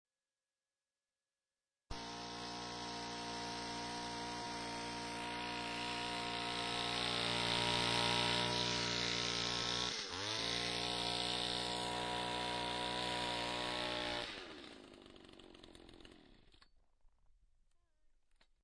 Ice Core Drill on Taylor Glacier
ice_drill_taylor.mp3